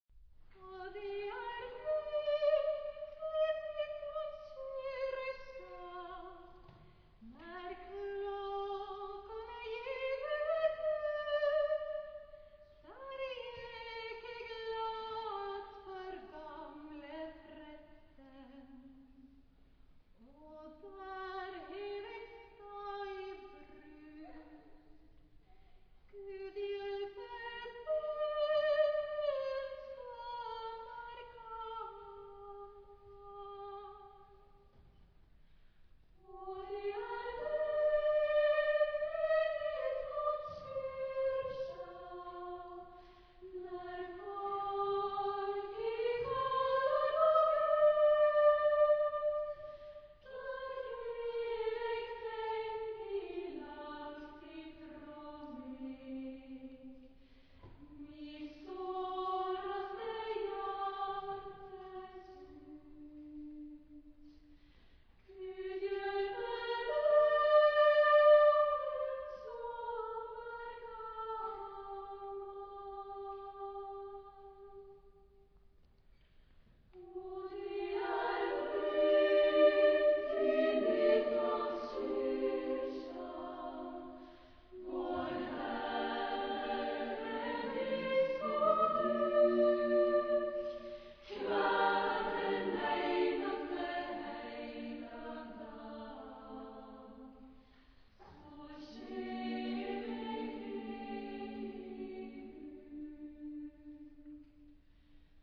Genre-Style-Forme : Sacré ; Chanson
Type de choeur : SSAA  (4 voix égales de femmes )
Tonalité : sol mineur